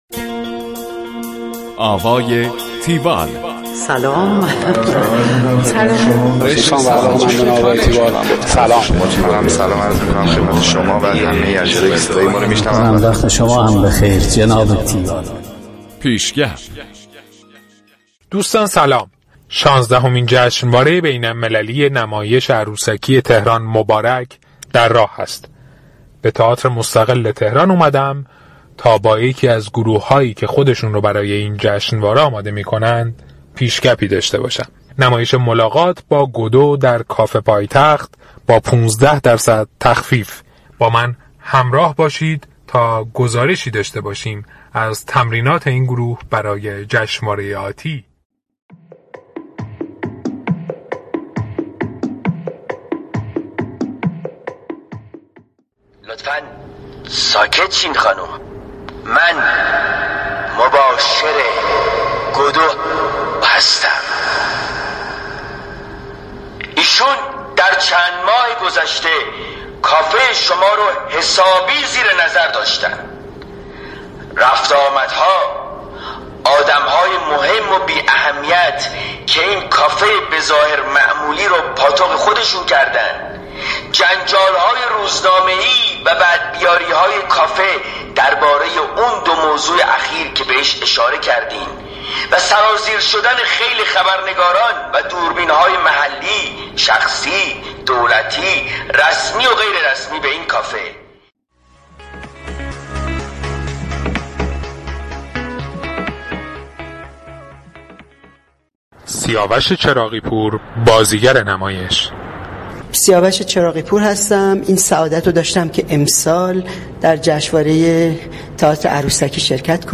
گزارش آوای تیوال از نمایش ملاقات با گودو در کافه پایتخت با پانزده درصد تخفیف